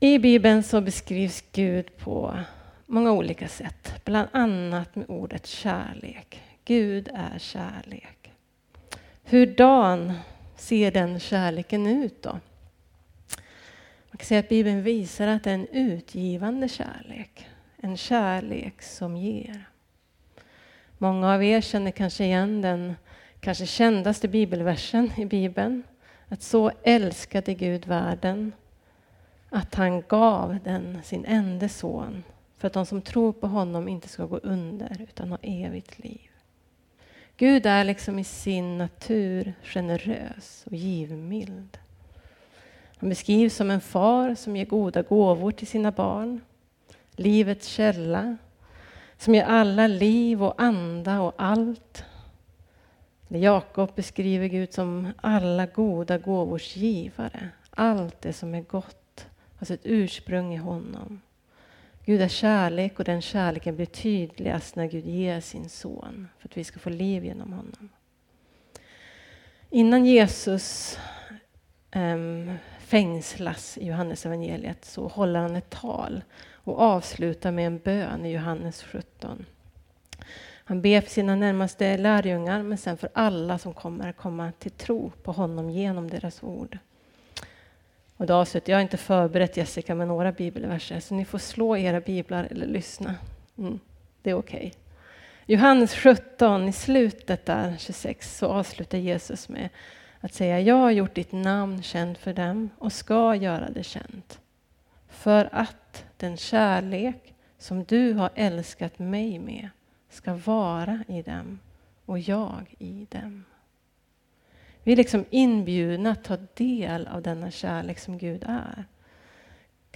Predikningar Elimkyrkan Gammelstad